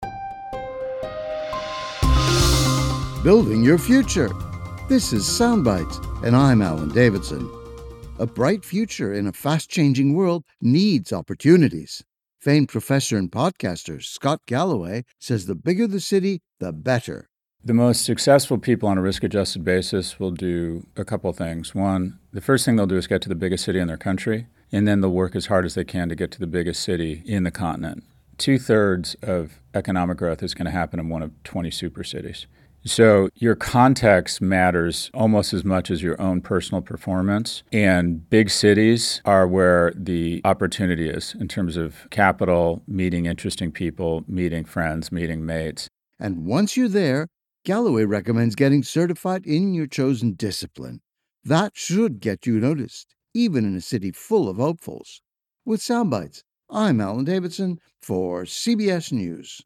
BONUS EPISODE: Hear my full interview with SCOTT GALLOWAY, NYU Professor, renowned podcaster and speaker extraordinaire at: